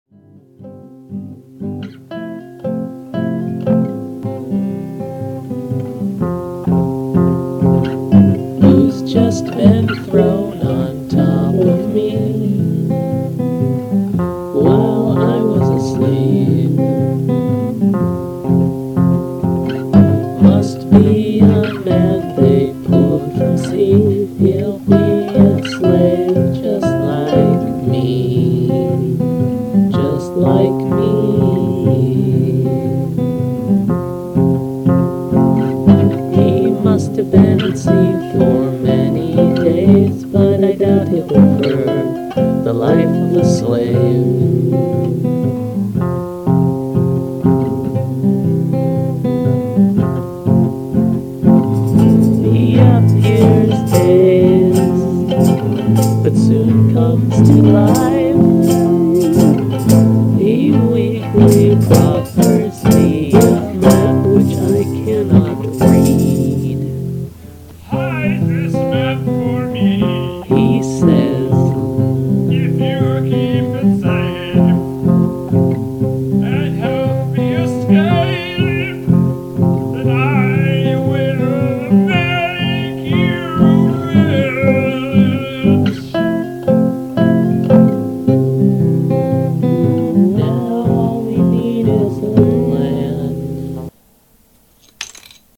guest vocals
tambourine